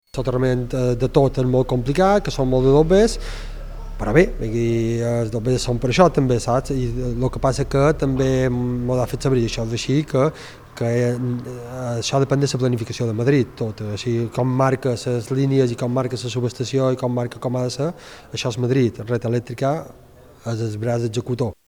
Asseguren que arribaran fins on faci falta per defensar el territori .Sentim el batle d’Artà, Manolo Galán.